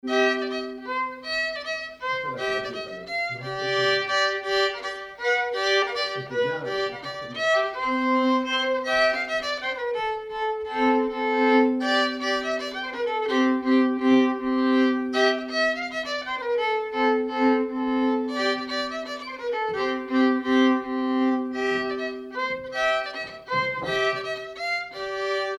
danse : mazurka
circonstance : bal, dancerie
Pièce musicale inédite